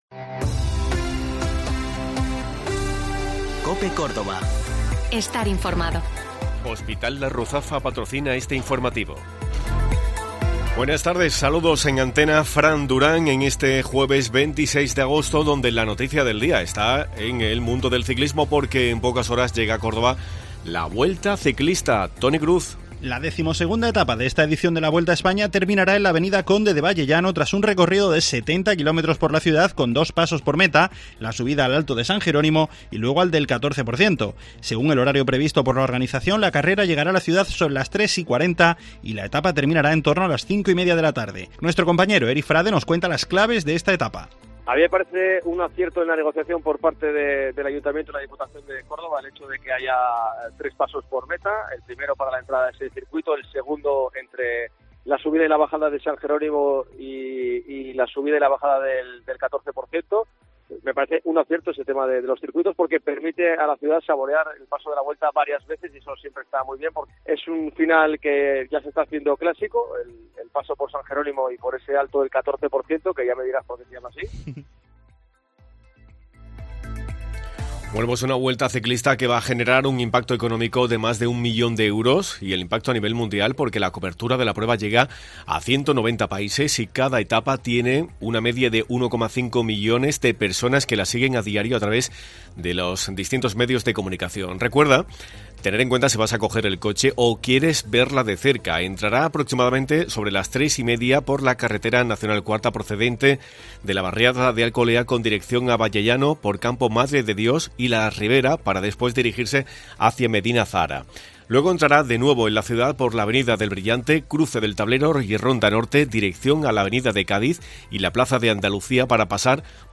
El consesjero de Salud y Familias Jesús Aguirre, ha explicado cómo está la situación.